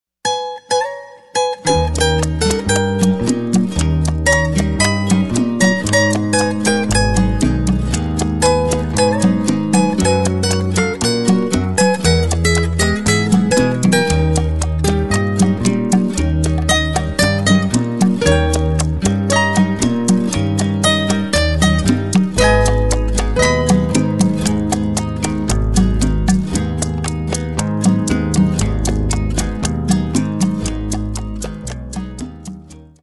Sonido bases musicales de Boleros - trío de Guitarras